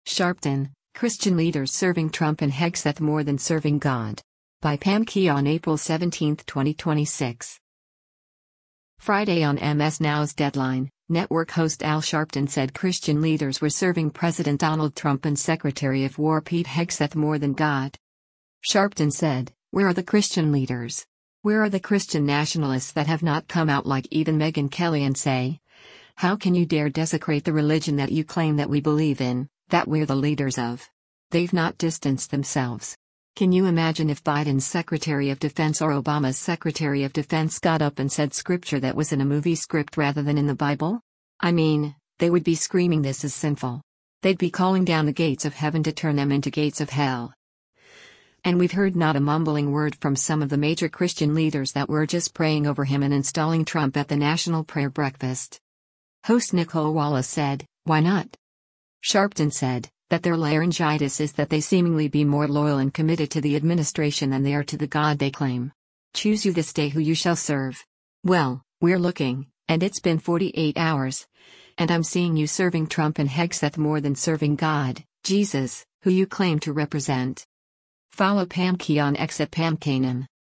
Friday on MS NOW’s “Deadline,” network host Al Sharpton said Christian leaders were serving President Donald Trump and Secretary of War Pete Hegseth more than God.